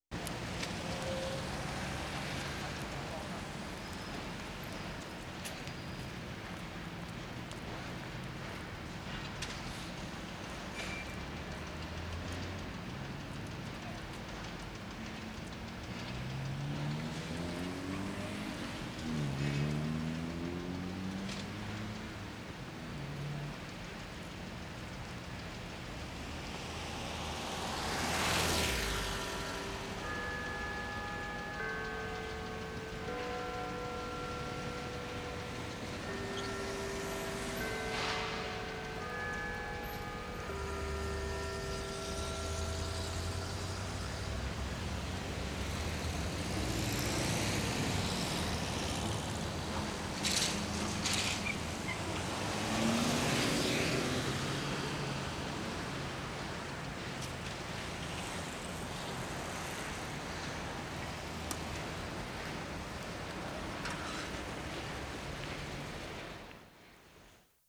DOWNTOWN VANCOUVER AND COMMERCIAL DRIVE May 17, 1973
ROYAL TRUST CHIMES 1'10"
3. Echoey urban soundscape, traffic-"wash" at distance and individual cars passing in foreground.
0'35" chimes from Pender and Howe.